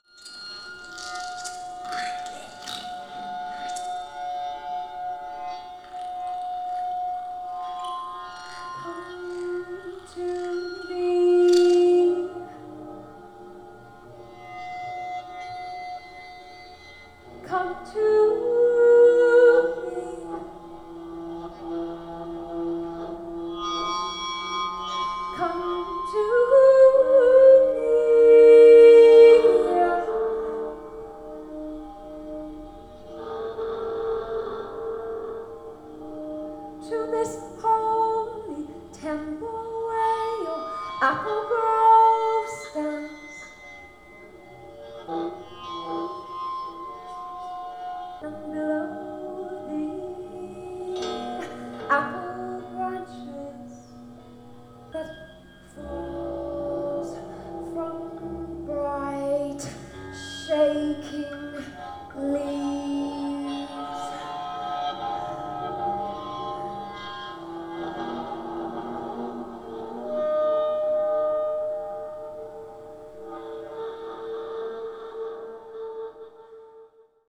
The Quiet Club continues to push the boundaries of sound making and listening by employing a wide range of sound producing devices including stones, homemade instruments, electronics, amplified textures, Theremins, field recordings, etc.
beach_audio_II.mp3